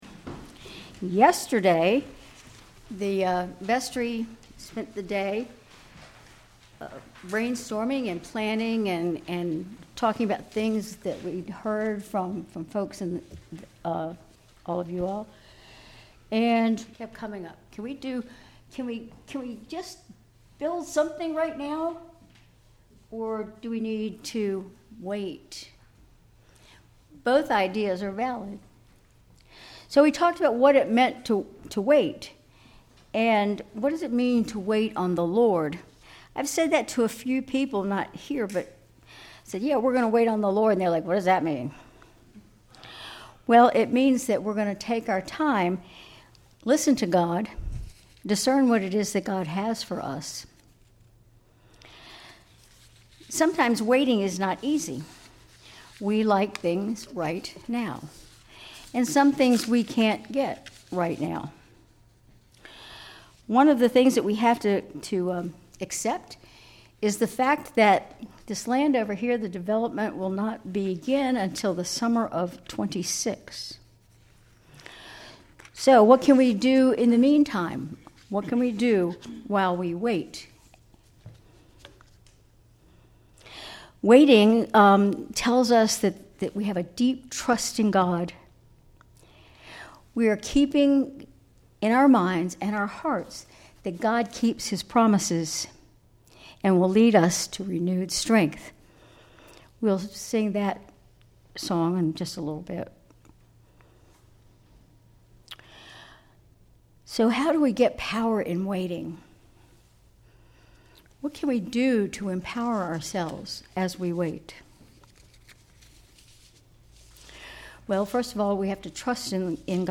Sermon April 6, 2025
Sermon_April_6_2025.mp3